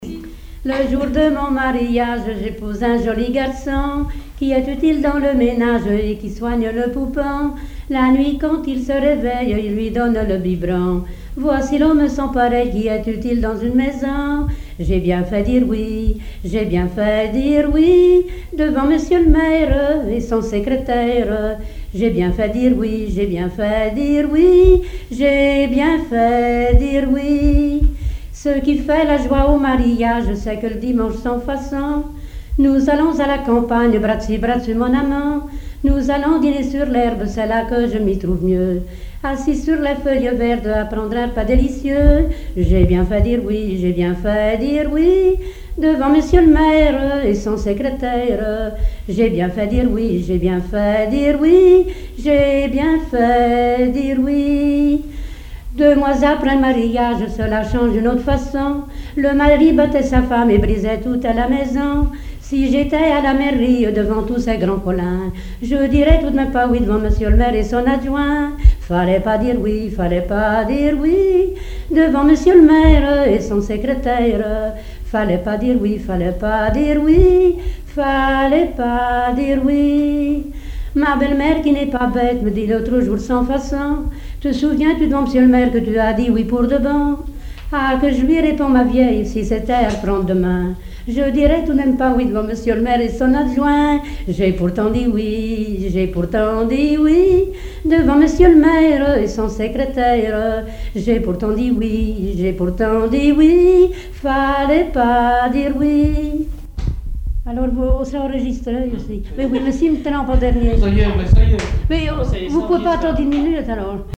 Genre strophique
Chansons et répertoire du musicien sur accordéon chromatique
Pièce musicale inédite